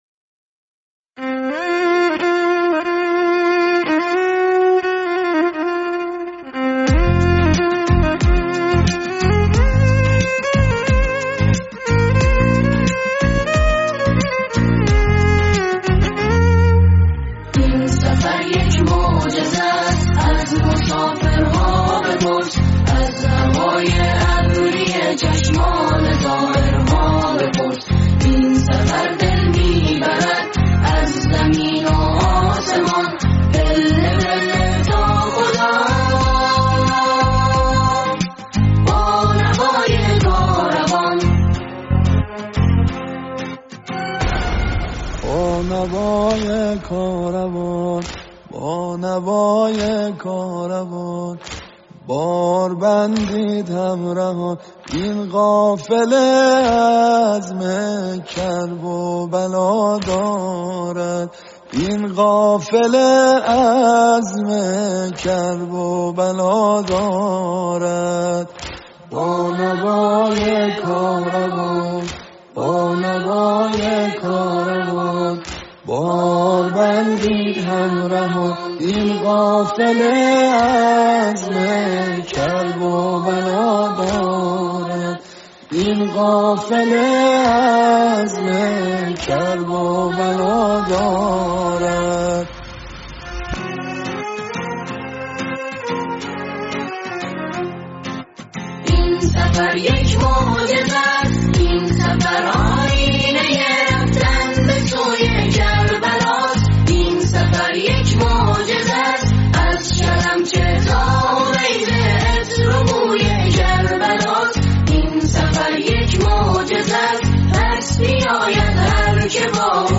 سرود ارکسترال با مضمون کاروان های راهیان نور این سفر یک معجزه است از مسافرها بپرس از هوای ابری چشمان زائرها بپرس/خواننده گروه كر